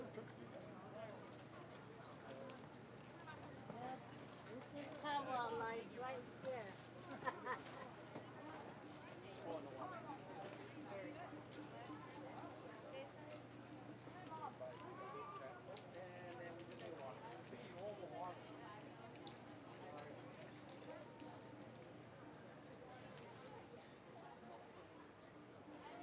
Menominee POWWOW